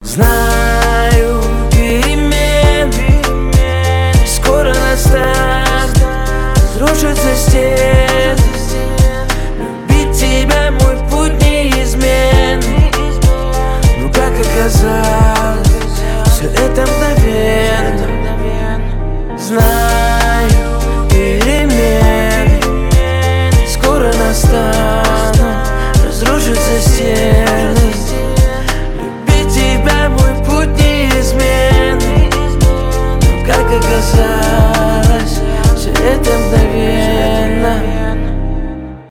rnb
поп